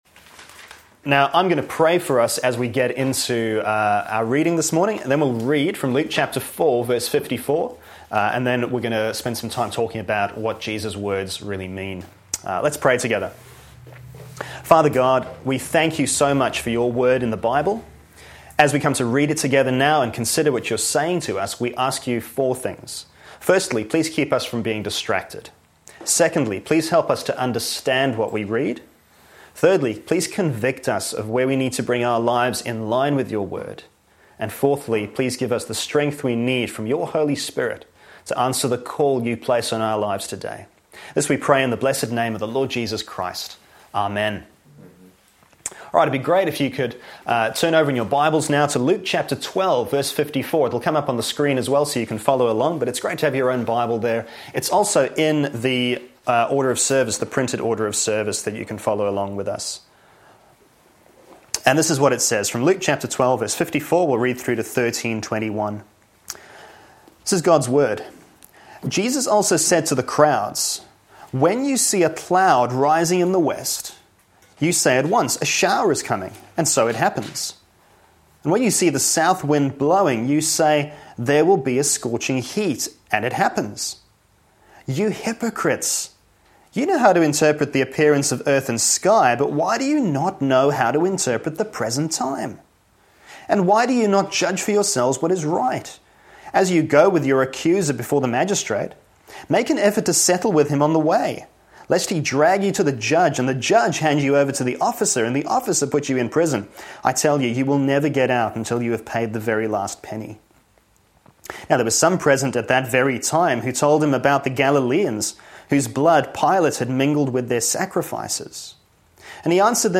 sermon podcasts